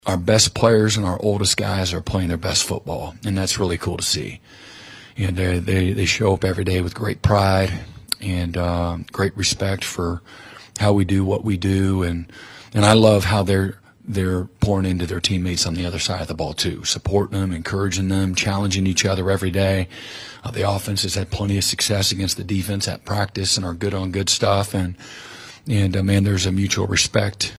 Oklahoma head football coach Brent Venables held his weekly press conference on Tuesday in Norman, as the Sooners try to keep some momentum from this past weekend.